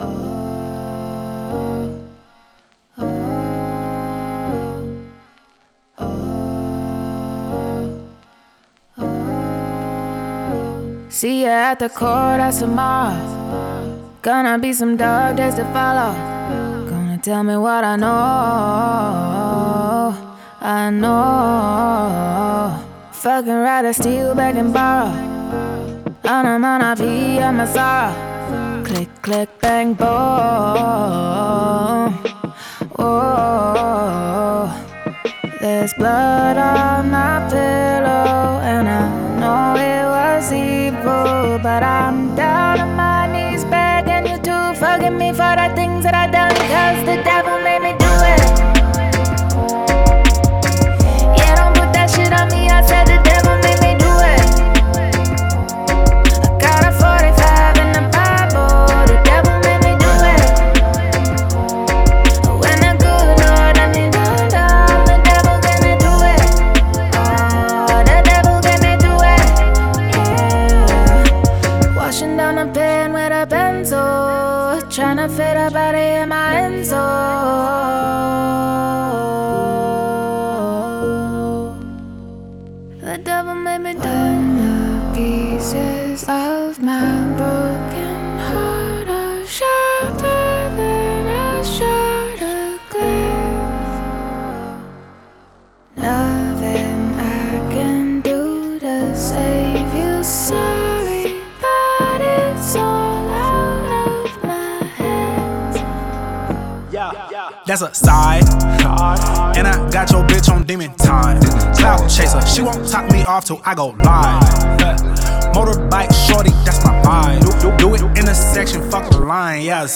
это динамичная композиция в жанре хип-хоп с элементами попа